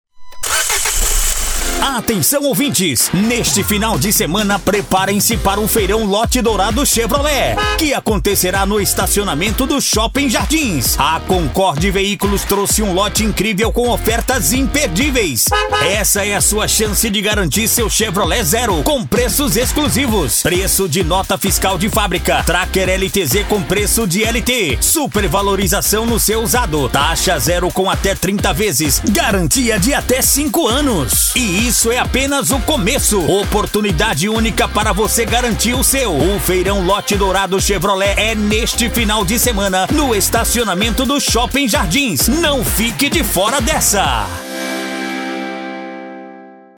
ALEGRE :